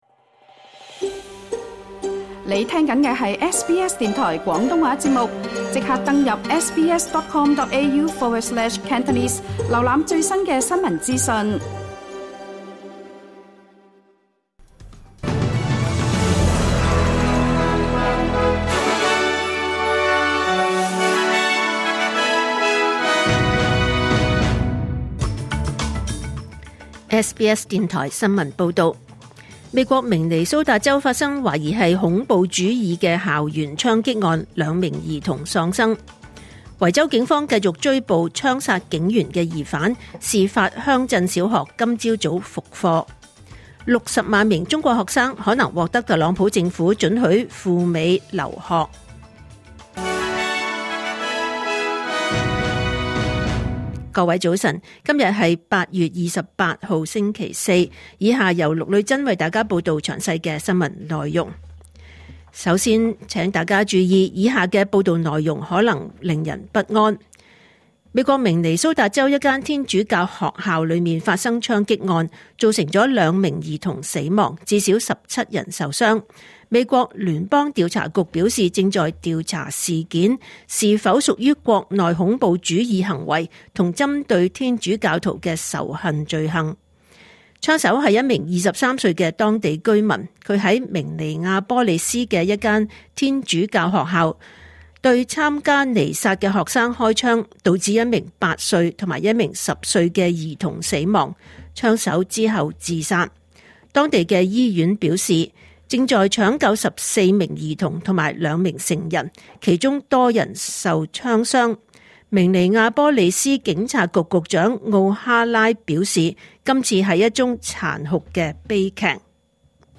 2025年8月28日 SBS 廣東話節目九點半新聞報道。